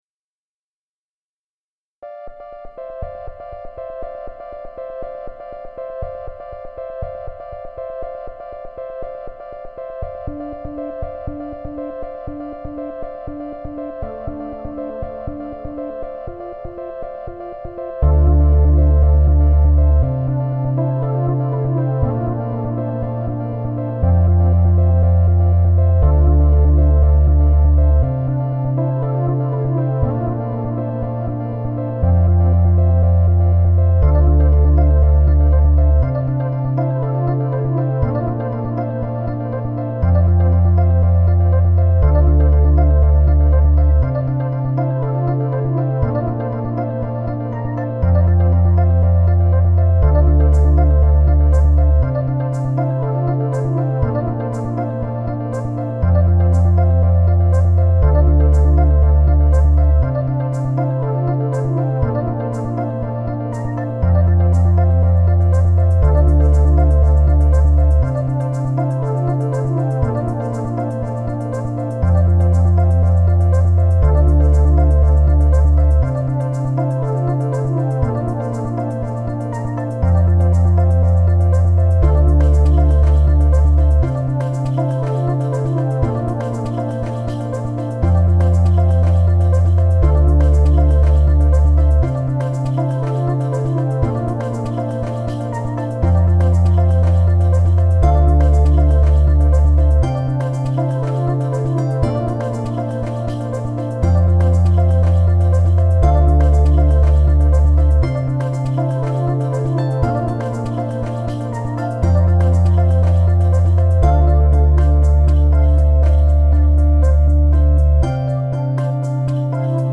[Chillout/Ambient] Clockwork (MP3)
I personally find it quite relaxing, especially when I listen to it with headphones.
In this area, I think the introduction of the 'tingy' sound from 1:40 on is a bit misplaced. And slightly off-tune I must say.
Definitely intriguing for me, as this would have been a well-produced instrumental in the 1980s.
I was waiting for the drum line to come in and strengthen the song but it just faded back to its roots...Still nice though.
it is the longest ostinato motive - it blends into a tone cloud